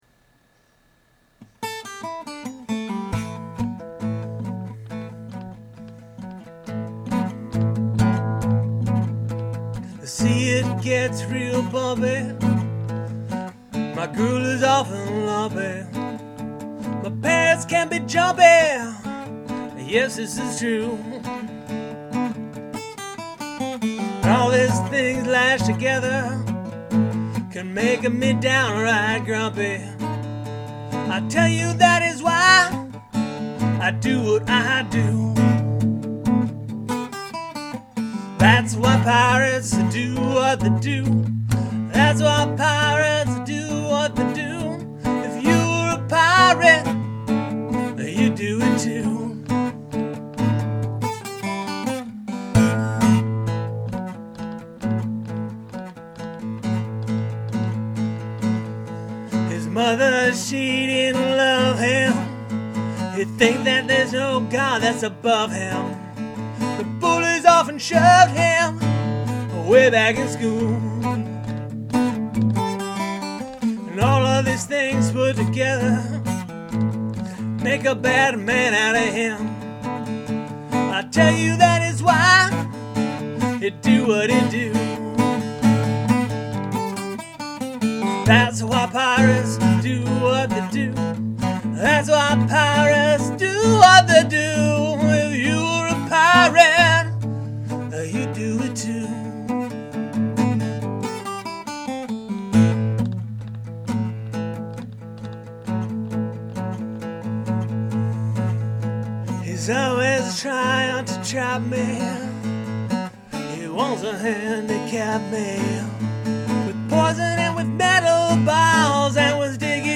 demo track of the above song